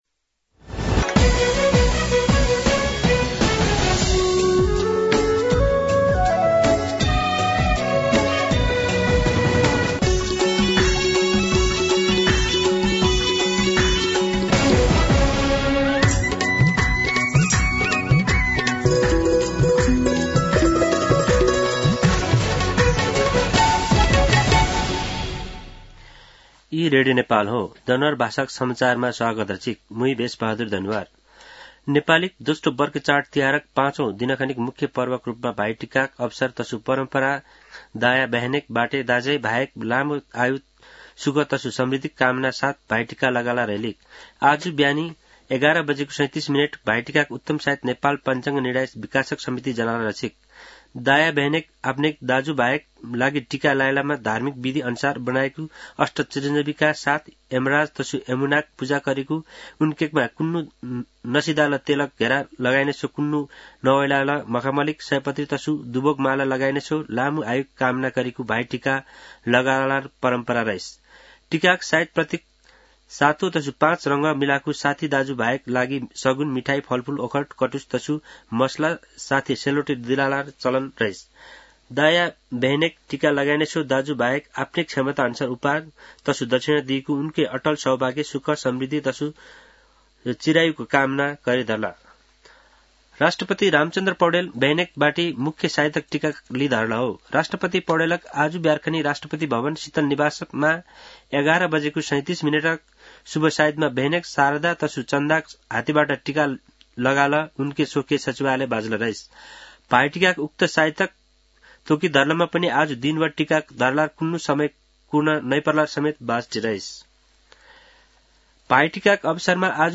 दनुवार भाषामा समाचार : १९ कार्तिक , २०८१
danuwar-News-.mp3